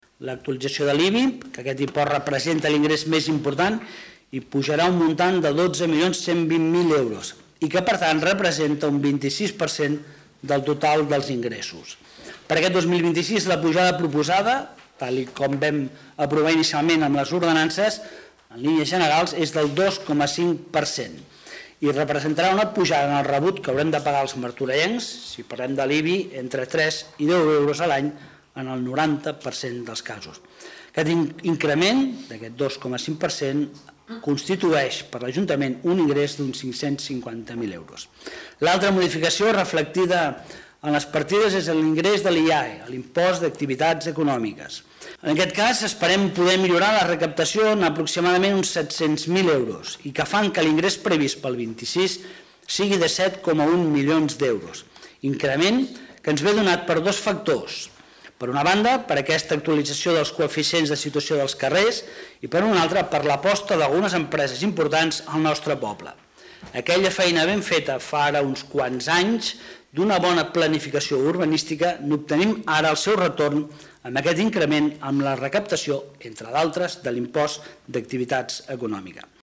Lluís Amat, regidor d'Hisenda i Règim Interior
Ple-Municipal-desembre-03.-Lluis-Amat.mp3